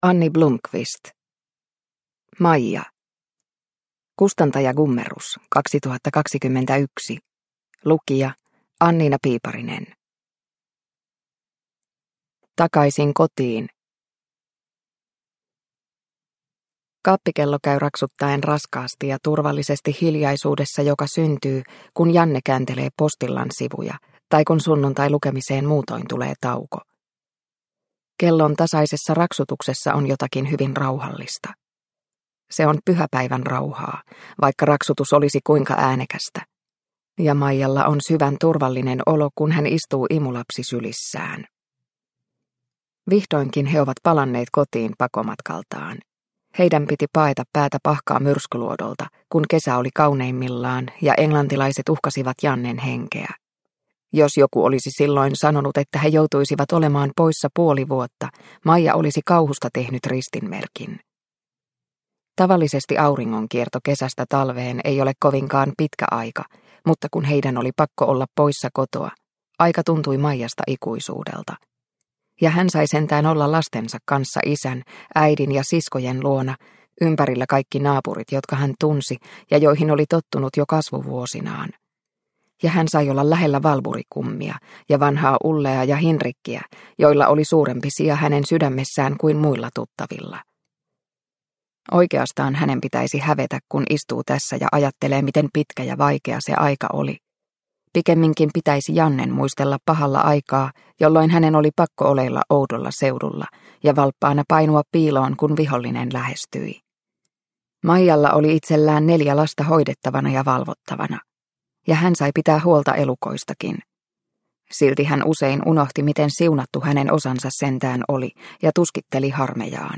Maija – Ljudbok – Laddas ner